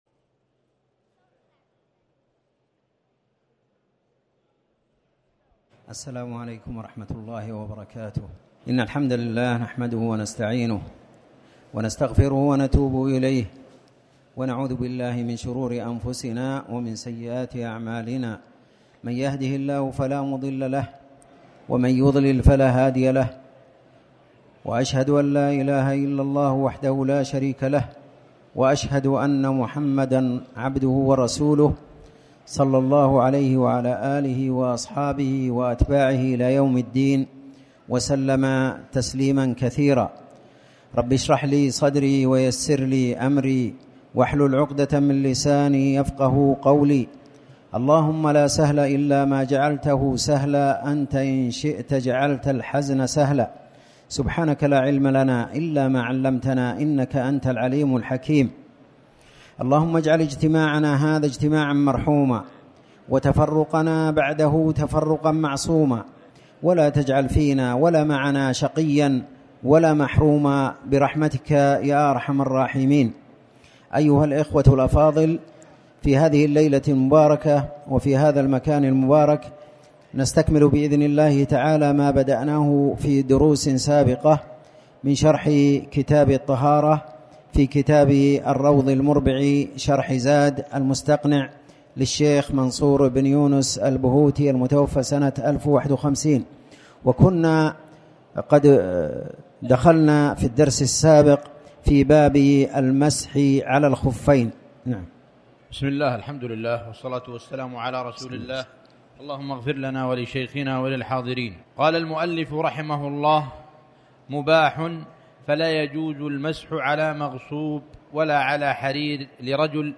تاريخ النشر ١٤ شعبان ١٤٣٩ هـ المكان: المسجد الحرام الشيخ